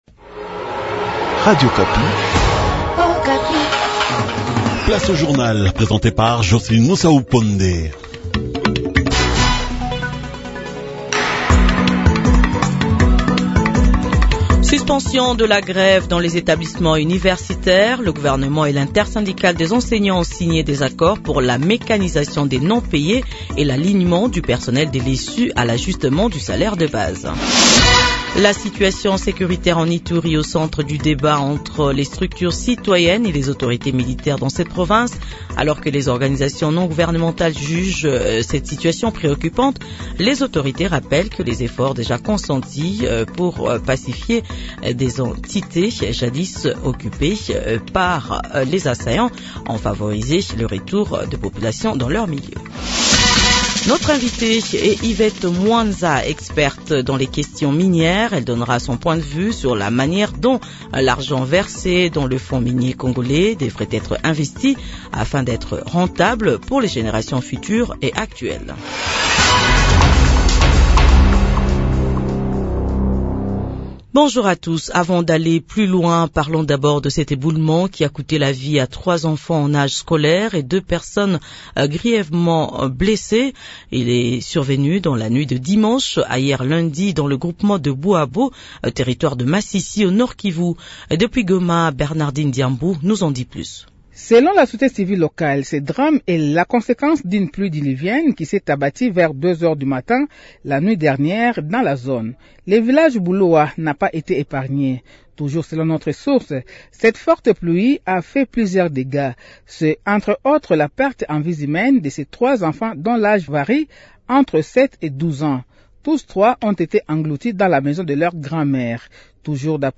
Journal Français Matin